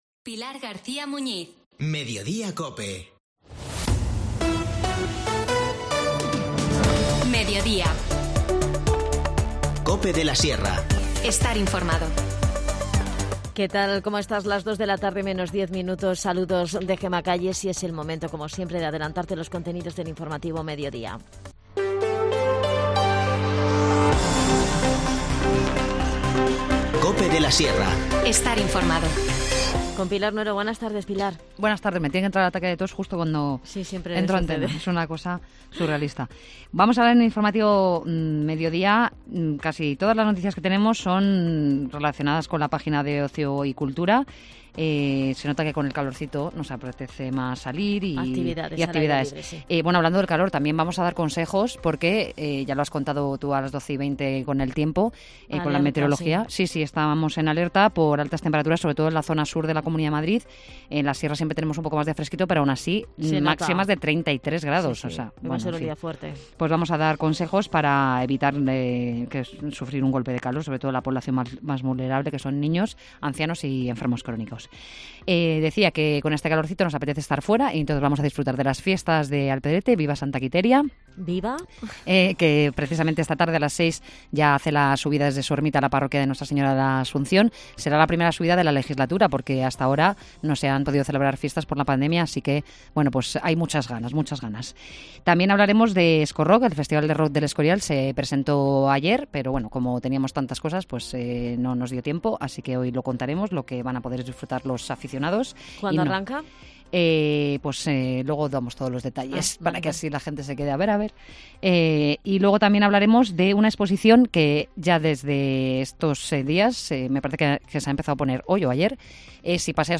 Charlamos con Adan Martínez, concejal de Comunicación en Collado Villalba, sobre la Feria de Andalucía que se va a celebrar del 20 al 22 de mayo en la Carpa Malvaloca. También nos adelanta el Programa de Actividades de Verano 2022 para niños y jóvenes.